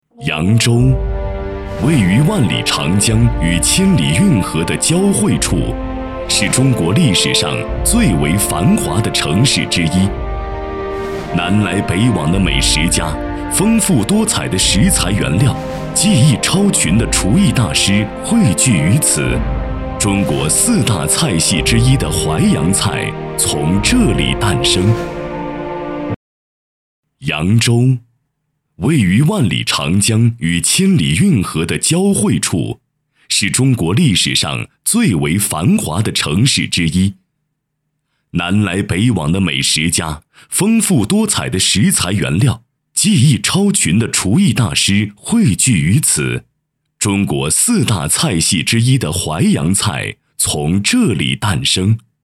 专题 扬州